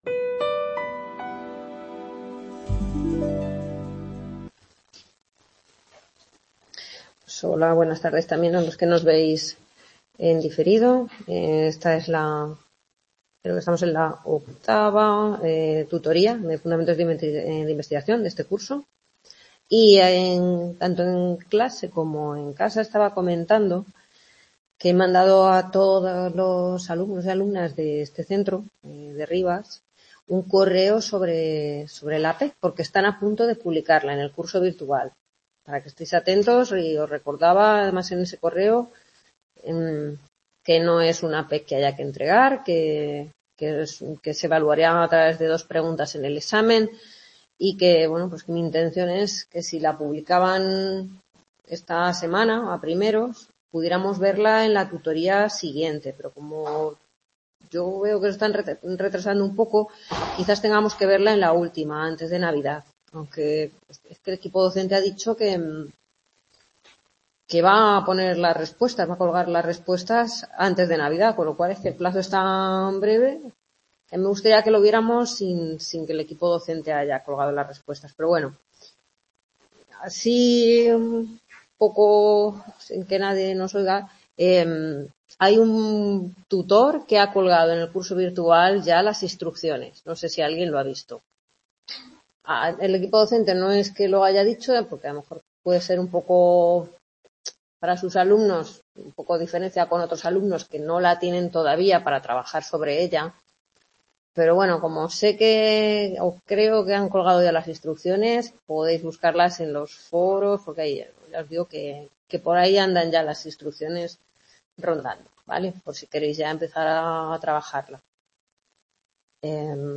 Grabación de la novena tutoría (primera parte) de la asignatura Fundamentos de investigación del Grado en Psicología impartida en el C.A. Rivas (UNED, Madrid). Corresponde a la actividad práctica del capítulo 7 de la asignatura, Diseños ex post facto.